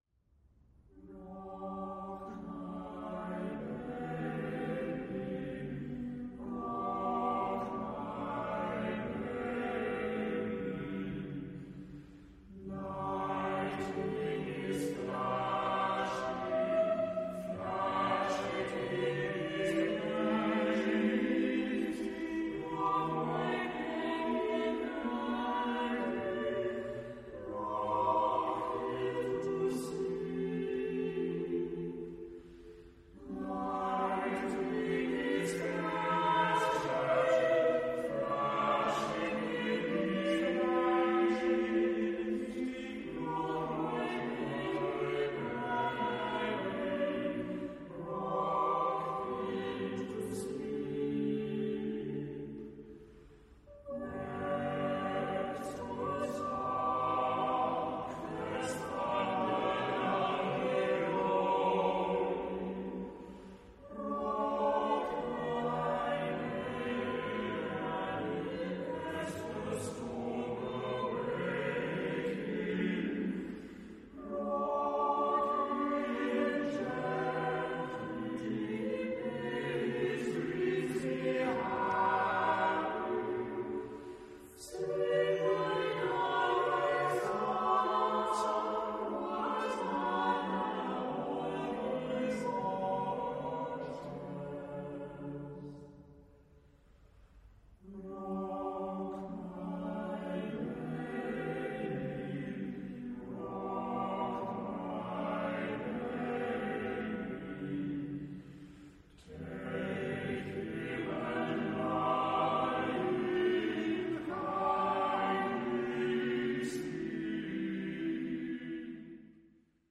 Type de choeur : SAATBB  (6 voix mixtes )
Tonalité : sol majeur
Sources musicologiques : Greek Folksong